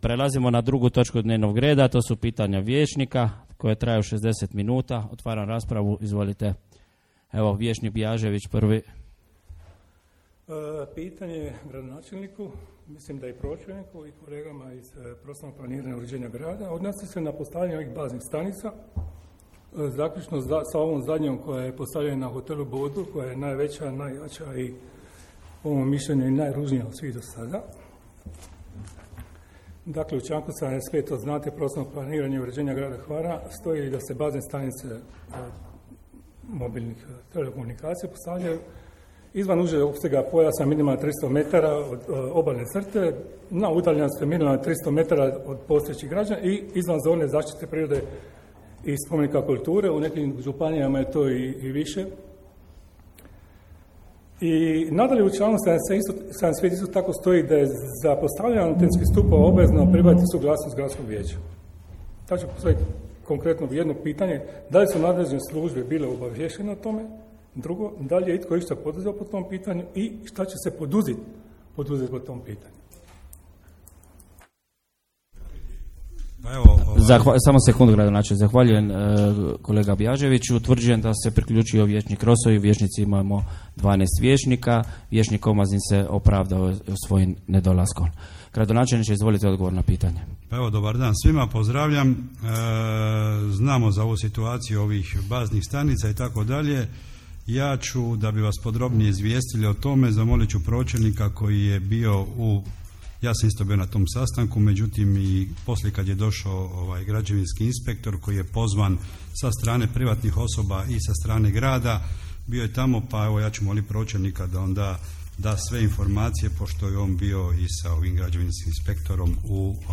Snimka 7. sjednice Gradskog vijeća Grada Hvara | Grad Hvar